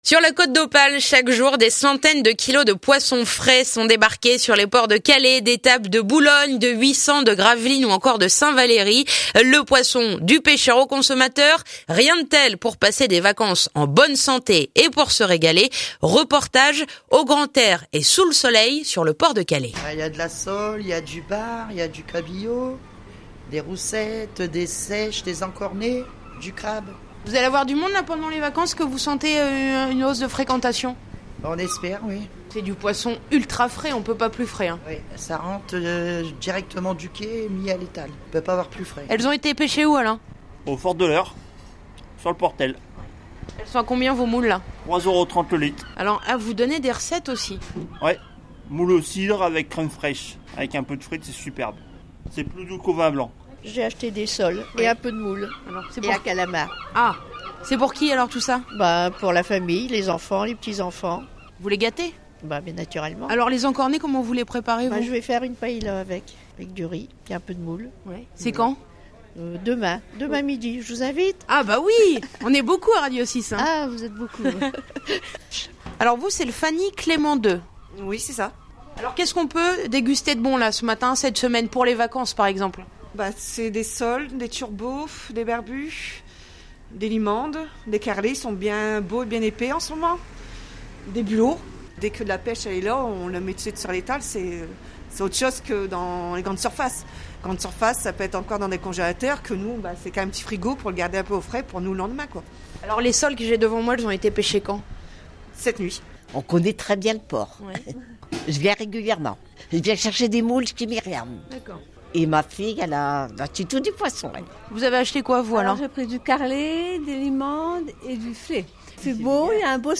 reportage sous le soleil